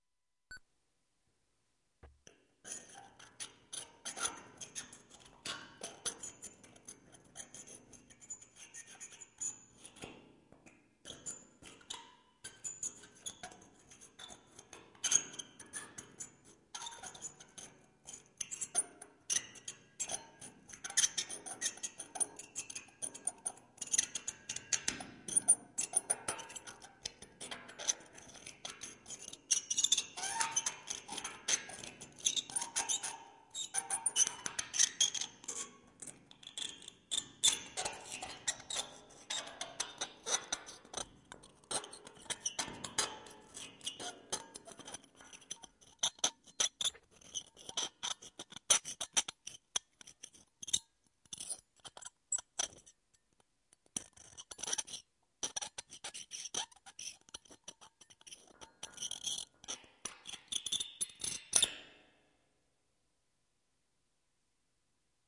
描述：金属门上有明亮的金属刮擦声，门体有适度的回响。
Tag: 金属 颤噪 混响